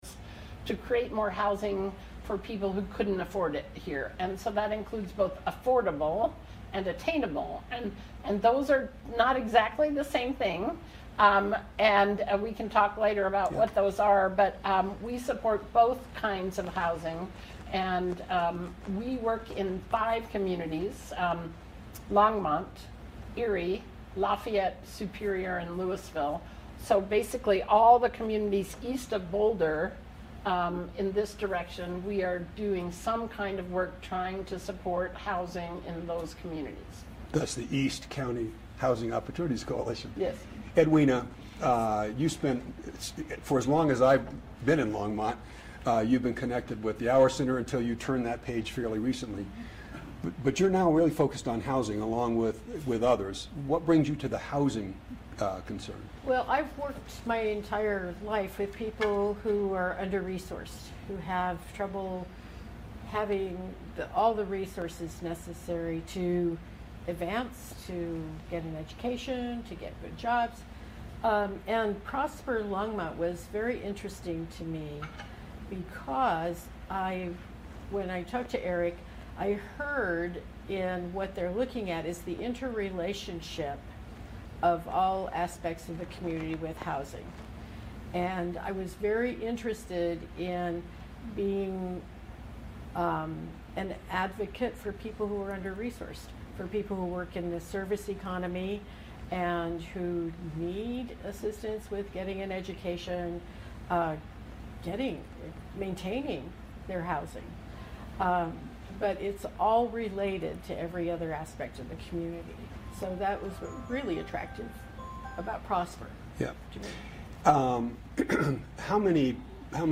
The Backstory on Housing in Longmont - Live at Longmont Public Media
The-Backstory-on-Housing-in-Longmont-Live-at-Longmont-Public-Media.mp3